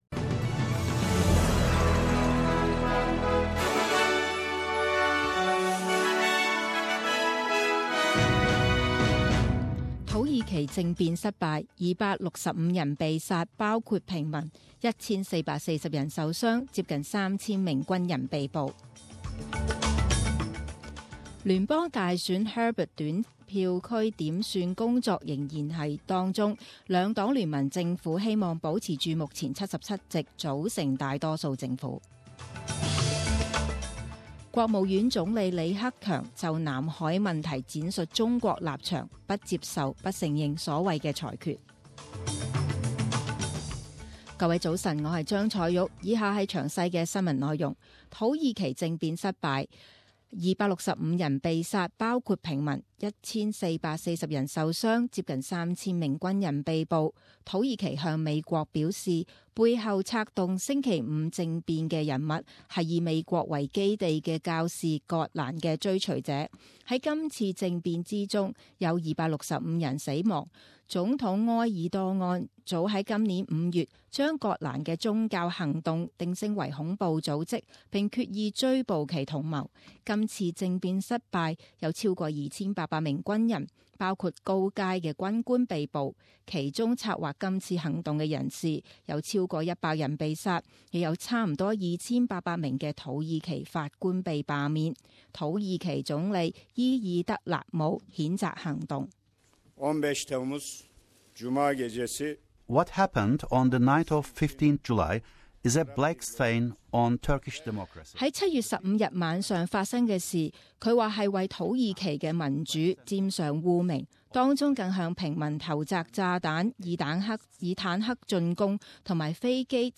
十点钟新闻报导 （七月十七日）